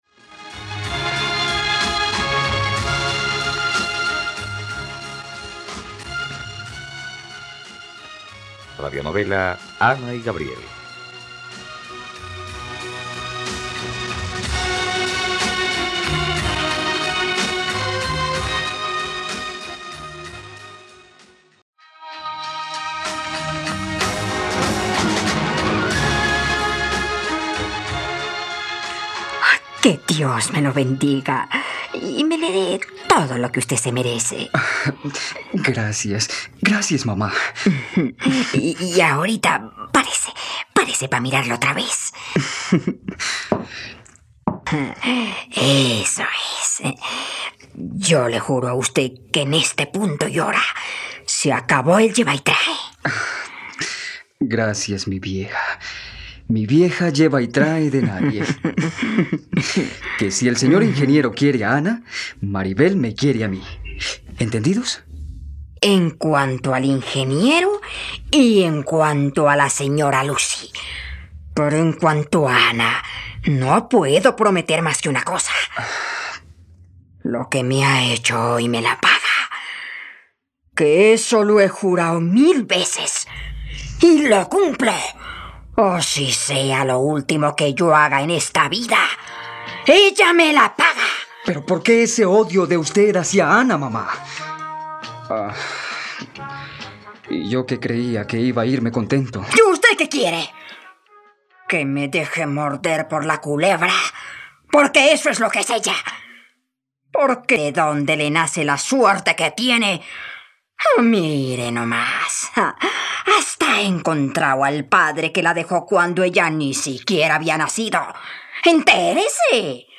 ..Radionovela. Escucha ahora el capítulo 71 de la historia de amor de Ana y Gabriel en la plataforma de streaming de los colombianos: RTVCPlay.